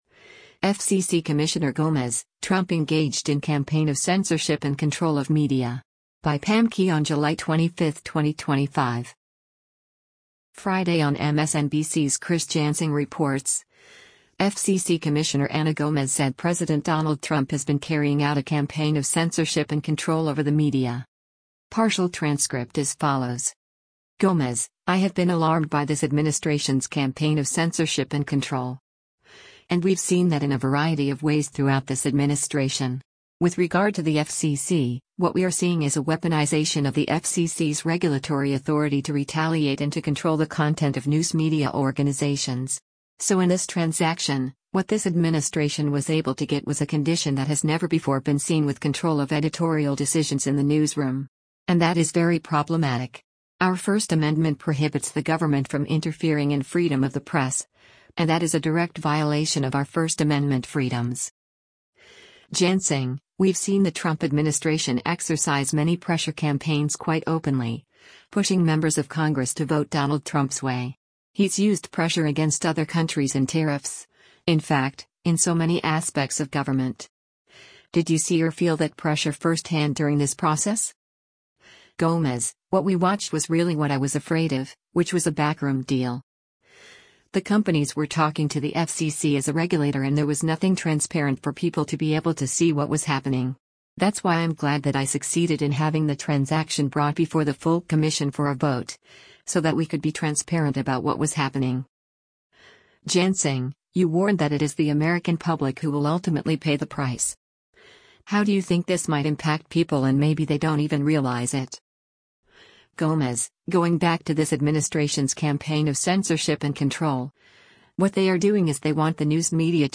Friday on MSNBC’s “Chris Jansing Reports,” FCC Commissioner Anna Gomez said President Donald Trump has been carrying out a “campaign of censorship and control” over the media.